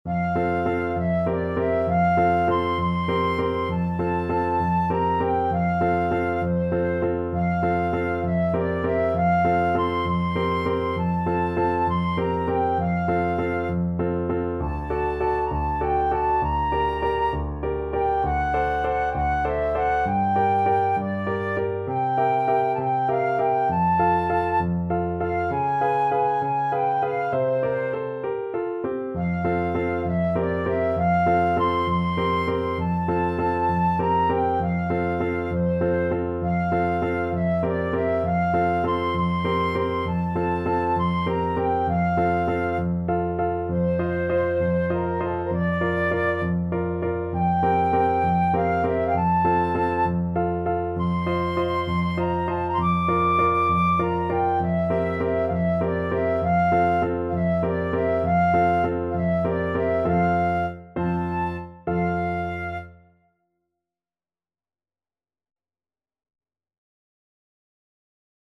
Flute
F major (Sounding Pitch) (View more F major Music for Flute )
. = 66 No. 3 Grazioso
6/8 (View more 6/8 Music)
Classical (View more Classical Flute Music)